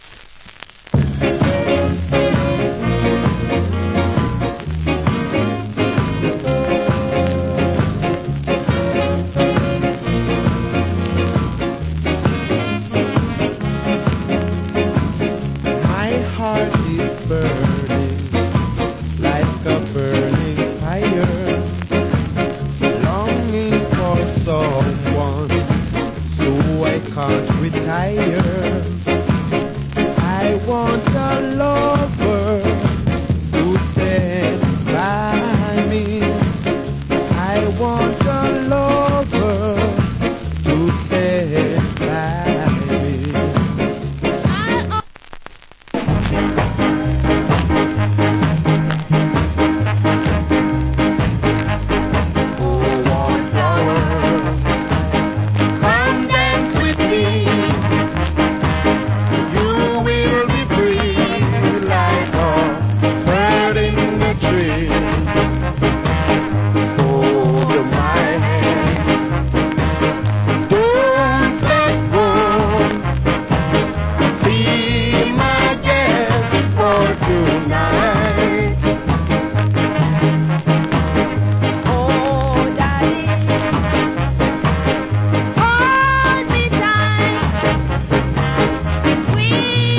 Good Duet Ska.